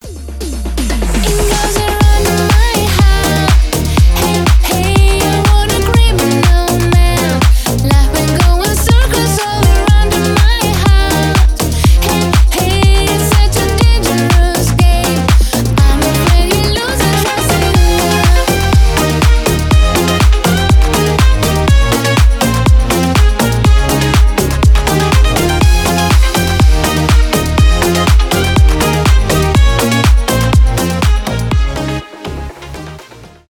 retromix
dance pop